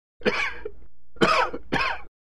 На этой странице собраны различные звуки кашля человека в высоком качестве.
Мужской кашель второй вариант